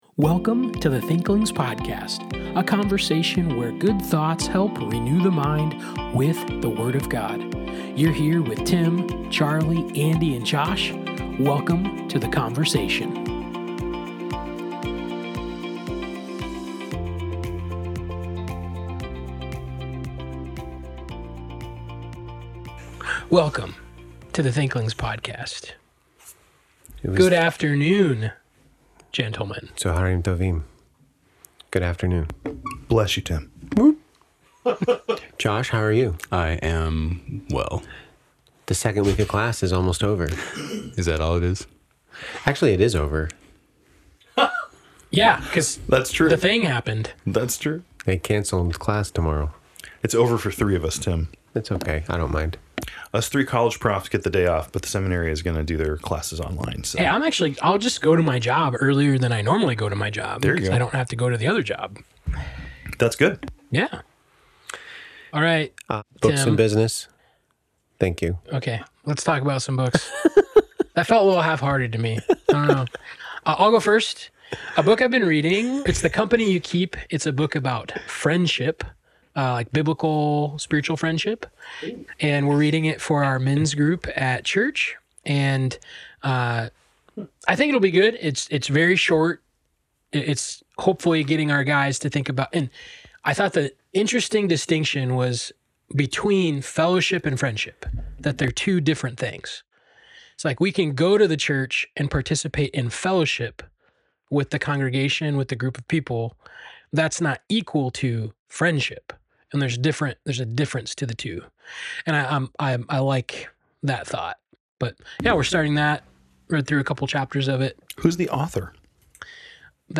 Thanks for tuning in to this week’s conversation!